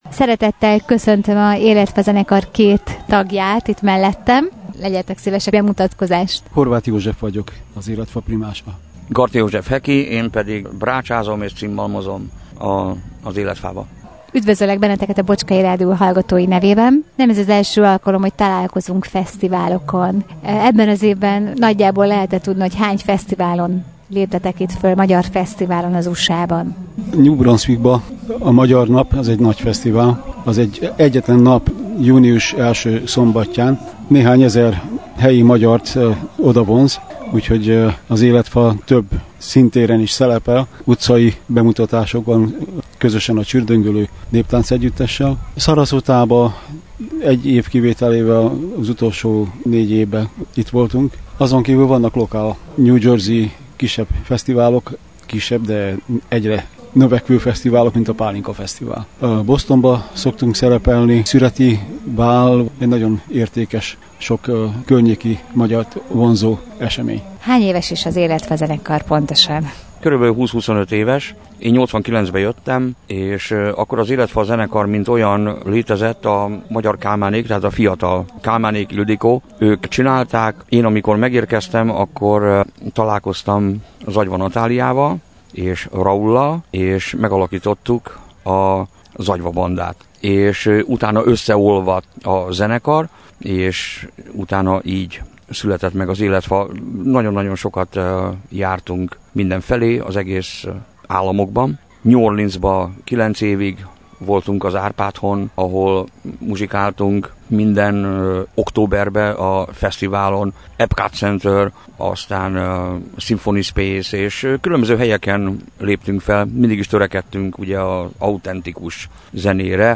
''Szeretjük csinálni, mert boldogok vagyunk, enélkül kevesebb lennék.'' interjú az Életfa zenekarral – Bocskai Rádió